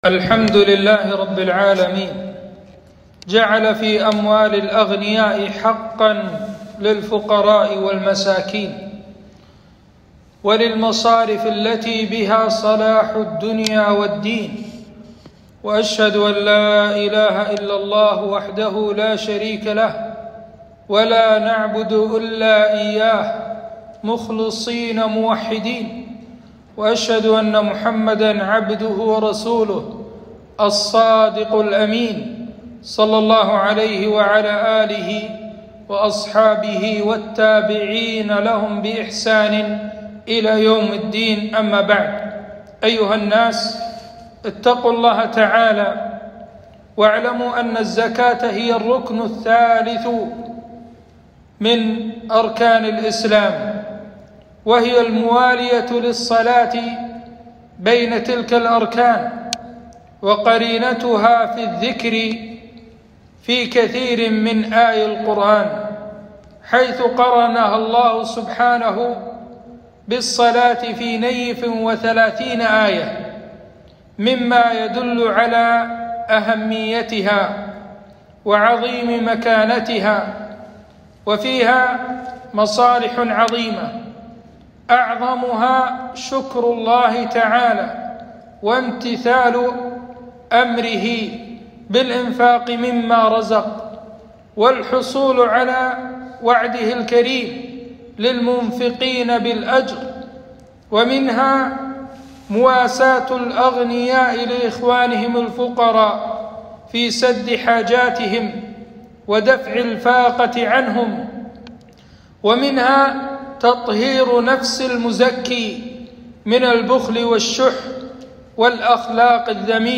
خطبة - عقوبة مانع الزكاة